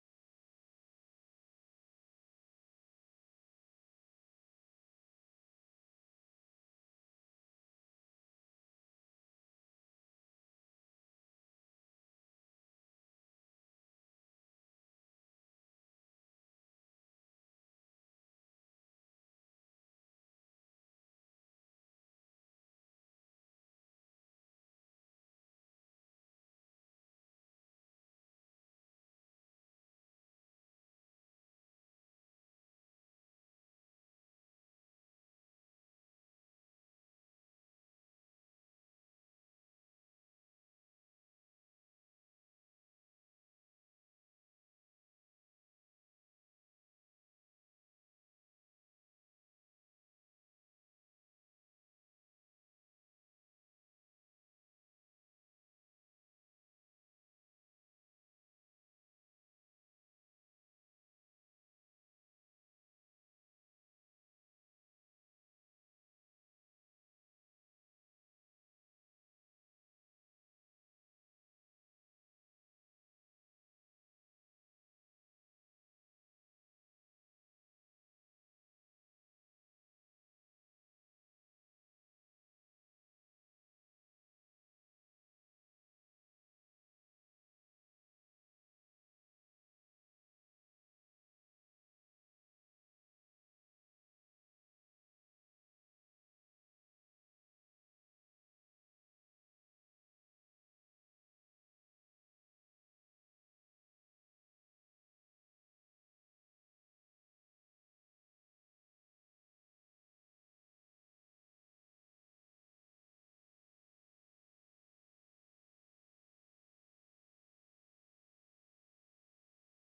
المحاضرة الثانية عشر _ الدورة التاسعه - توحيد المرسل ( 14/3/2018 ) العقيدة